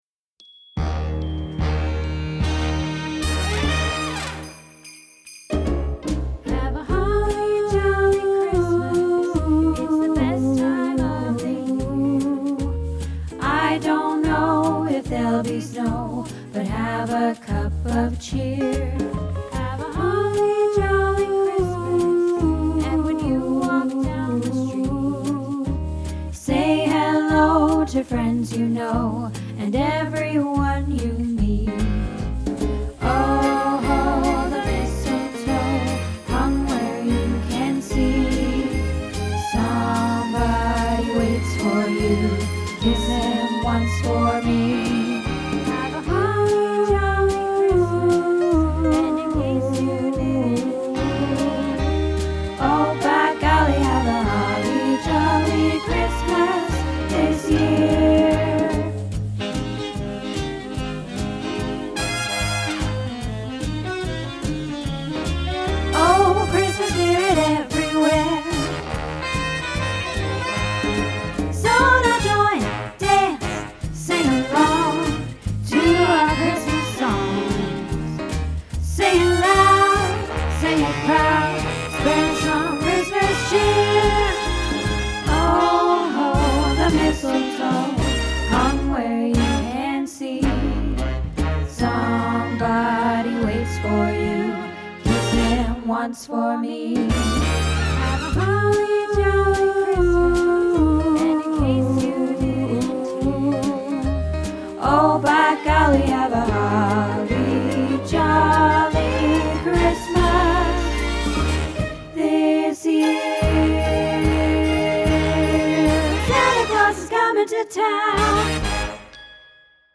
Holly Jolly Christmas - Tenor